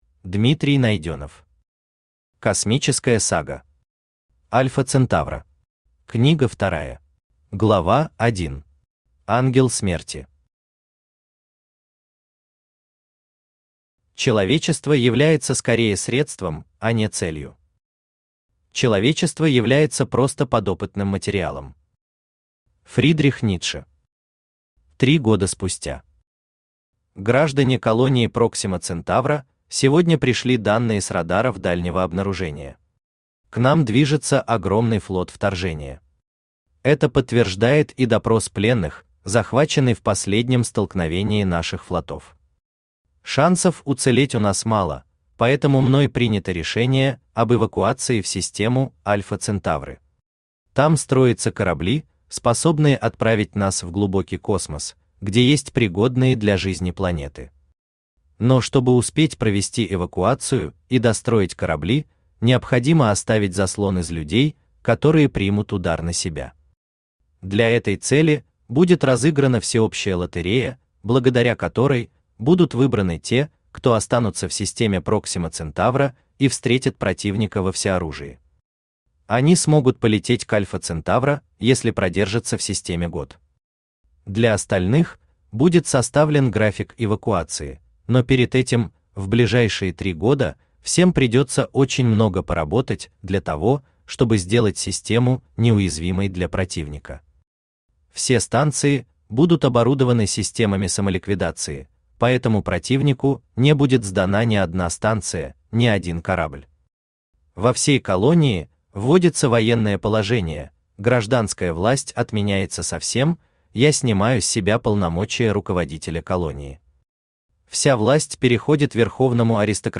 Aудиокнига Космическая сага. Альфа Центавра. Книга вторая Автор Дмитрий Александрович Найденов Читает аудиокнигу Авточтец ЛитРес. Прослушать и бесплатно скачать фрагмент аудиокниги